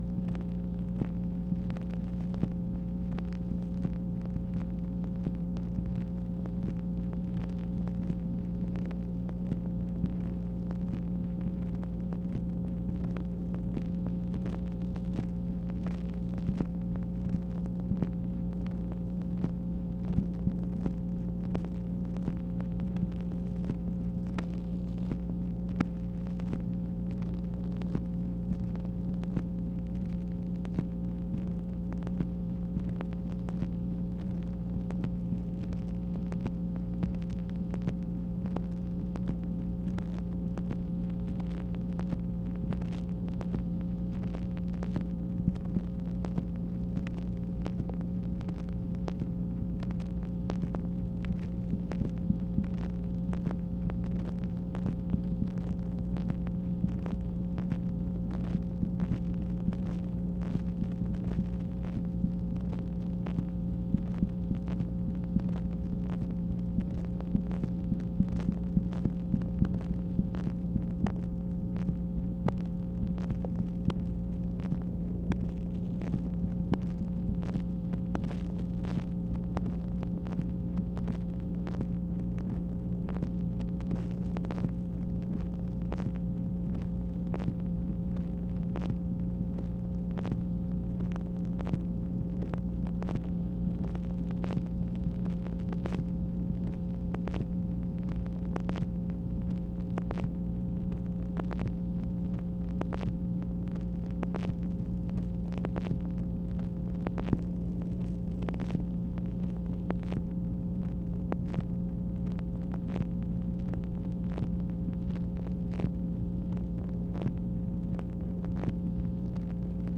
MACHINE NOISE, September 14, 1966
Secret White House Tapes | Lyndon B. Johnson Presidency